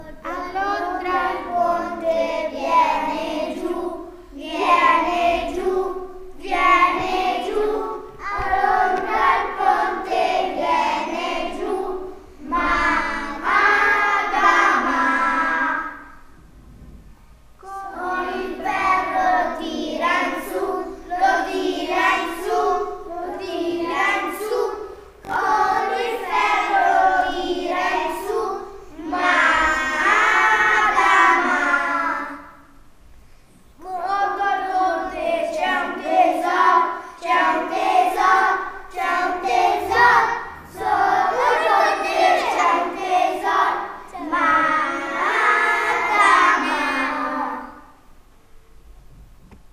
(Classe 1^, Scuola Primaria di Castel del Piano, registrazione del nono giorno di scuola)